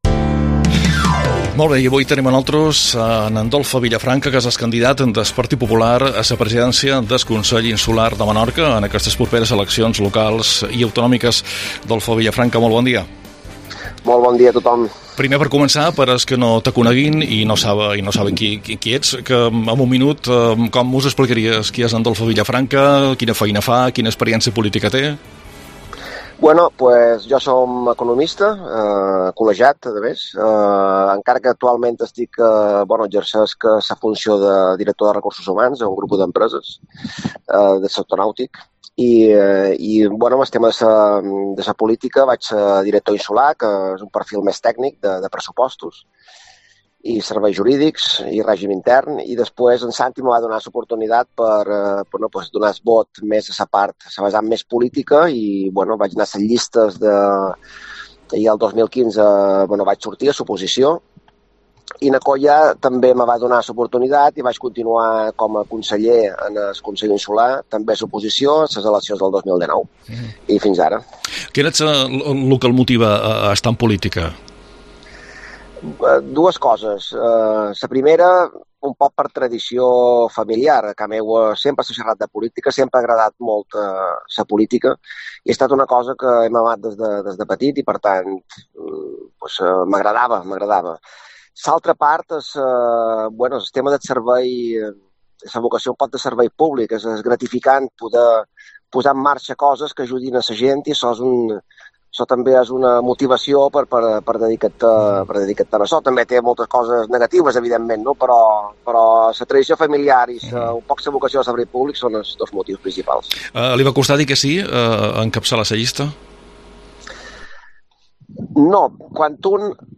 Entrevistes eleccions locals 2023